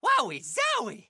Mario during a powerup to Elephant Mario in Super Mario Bros. Wonder.